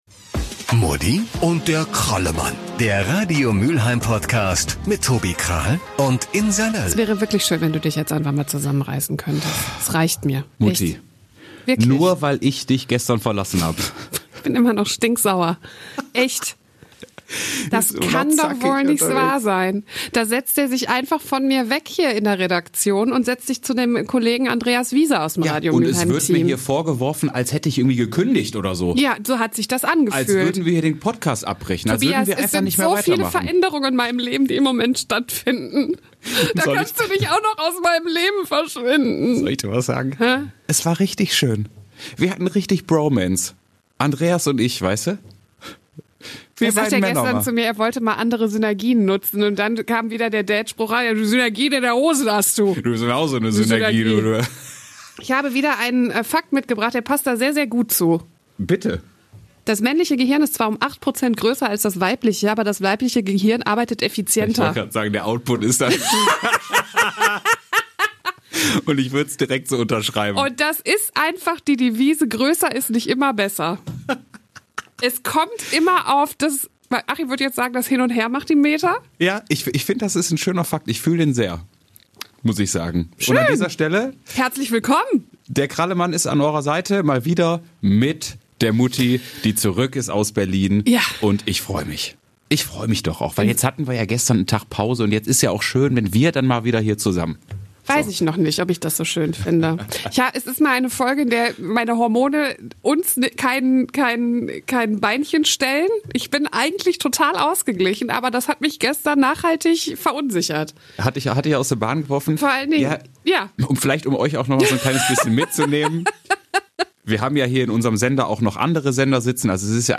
wieder im Studio